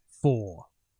Voices / Male